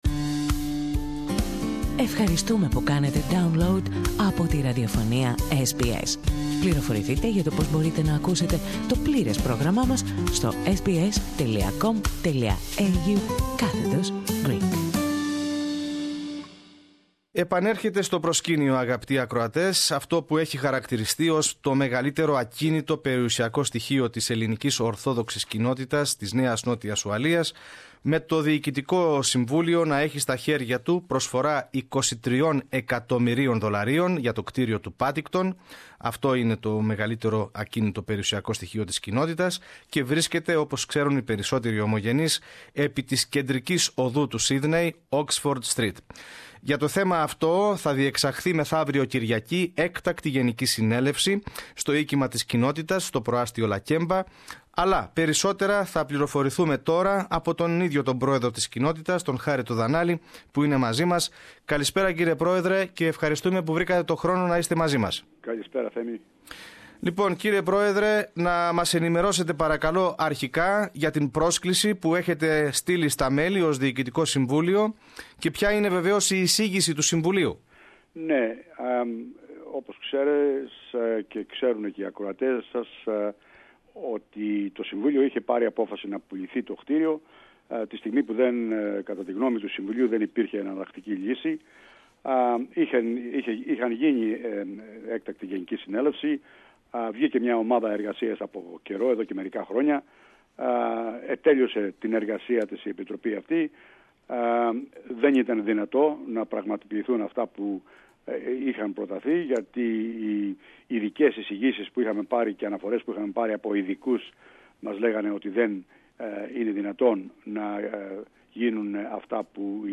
Τα μέλη της Ελληνικής Ορθόδοξης Κοινότητας της ΝΝΟ, καλούνται σε έκτακτη Γενική Συνέλευση την ερχόμενη Κυριακή προκειμένου να αποφασίσουν για την πώληση του μεγαλύτερου ίσως ακινήτου που διαθέτει η Κοινότητα, στο κεντρικό προάστειο. Περισσότερα στην συνέντευξη